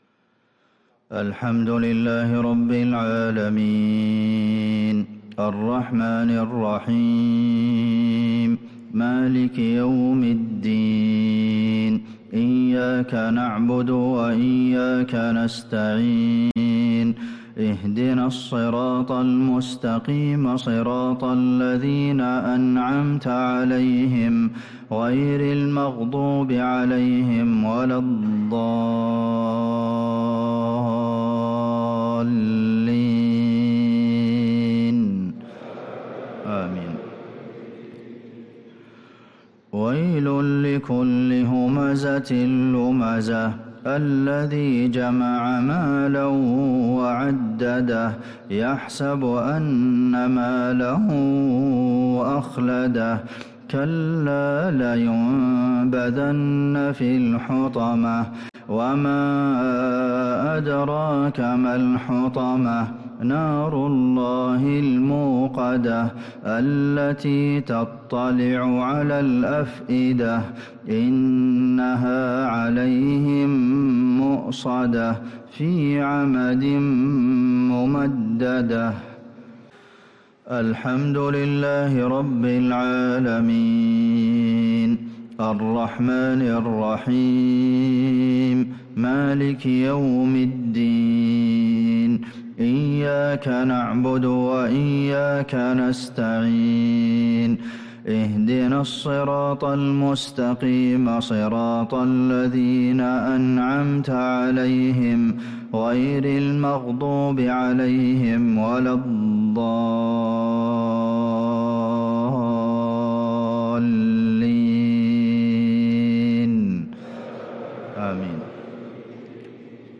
صلاة المغرب ٥-٨-١٤٤٦هـ | سورة الهمزة و المسد كاملة | Maghrib prayer from Surah al-Humazah & Al-Masad | 4-2-2025 > 1446 🕌 > الفروض - تلاوات الحرمين